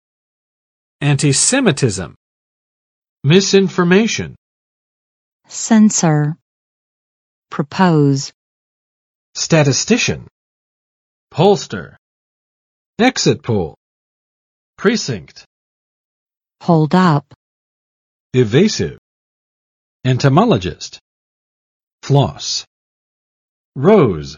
[͵æntɪˋsɛmɪtɪzəm] n. 反犹太主义